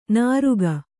♪ nāruga